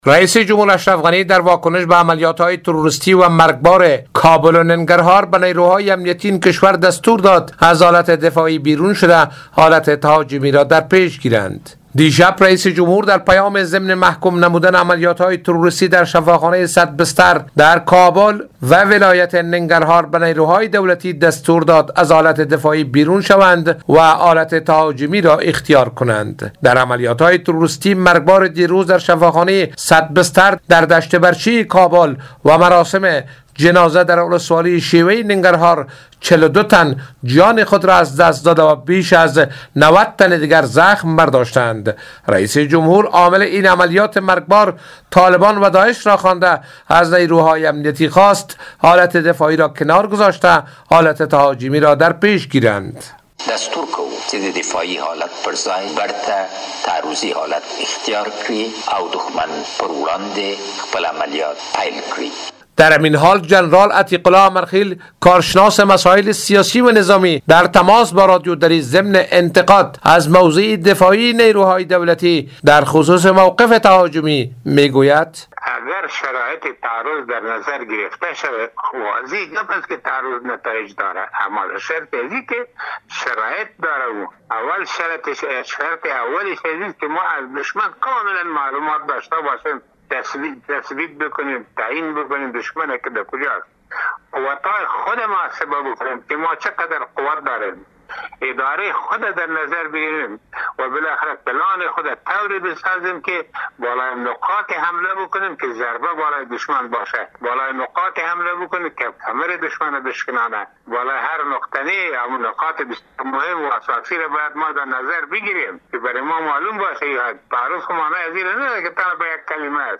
جزئیات این خبر در گزارش همکارمان